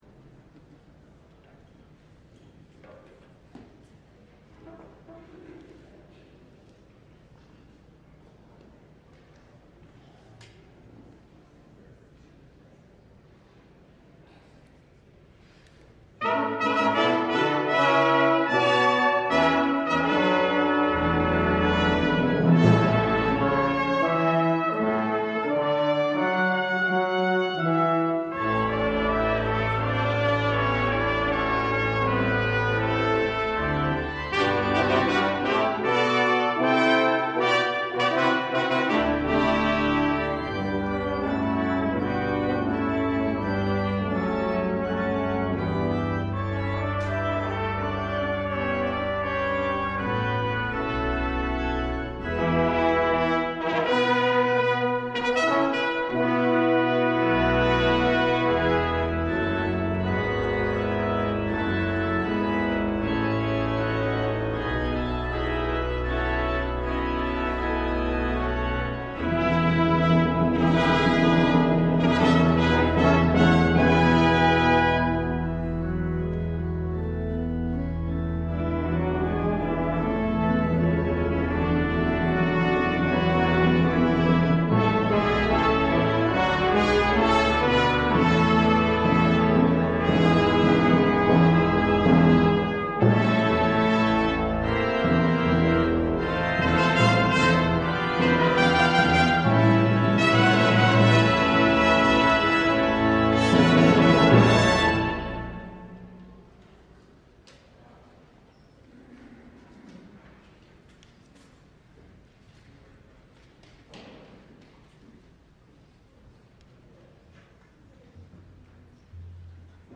LIVE Morning Service - Easter Morning Worship: The Two Enemies of Easter